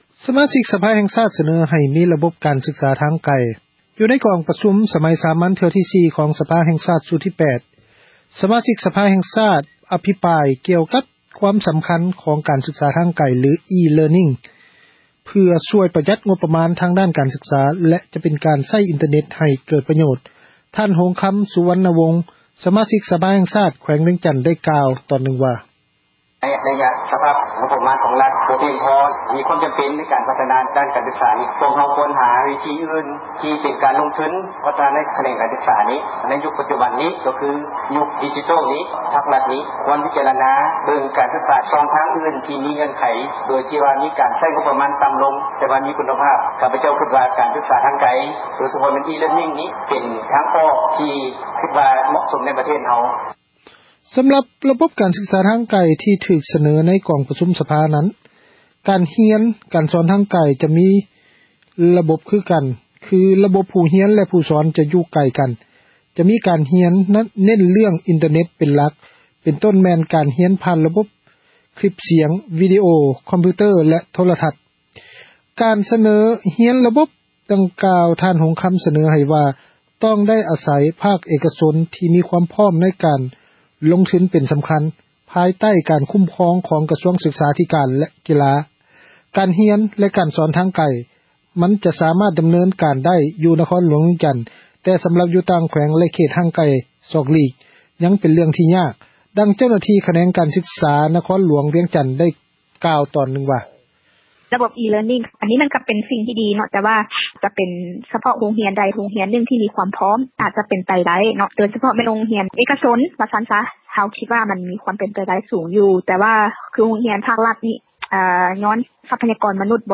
ໃນກອງປະຊຸມສະໄໝສາມັນເທື່ອທີ 4 ຂອງສະພາແຫ່ງຊາດຊຸດທີ 8 ສະມາຊິກສະພາແຫ່ງຊາດ ອະພິປາຍກ່ຽວກັບ ຄວາມສຳຄັນ ຂອງ ການສຶກສາ ທາງໄກ ຫລື E-Learning  ເພື່ອປະຢັດ ງົບປະມານ ທາງດ້ານການສຶກສາ ແລະຈະເປັນການໃຊ້ ອິນເຕີເນັດໃຫ້ເກີດ ປະ ໂຫຍດ. ທ່ານ ຫົງຄຳ ສຸວັນນະວົງ ສະມາຊິກ ສະພາແຫ່ງຊາດ ໄດ້ກ່າວຕອນນຶ່ງວ່າ: